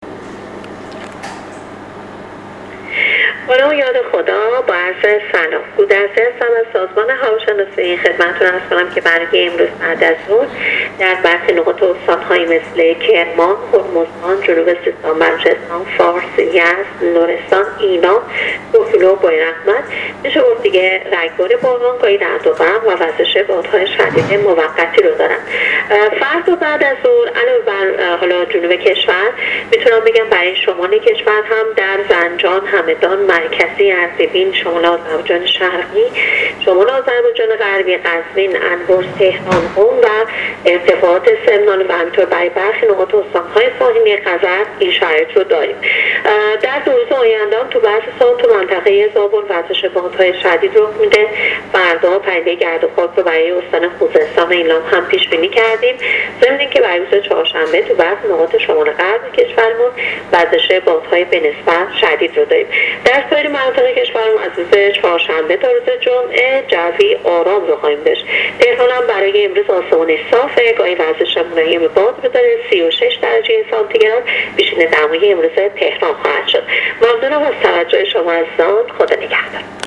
گزارش رادیو اینترنتی از آخرین وضعیت آب و هوای ۱۲ خرداد: